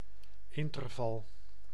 Ääntäminen
Synonyymit tussenruimte Ääntäminen Tuntematon aksentti: IPA: /ˈɪntərvɑl/ Haettu sana löytyi näillä lähdekielillä: hollanti Käännös Konteksti Ääninäyte Substantiivit 1. interval musiikki US 2. window UK US Suku: n .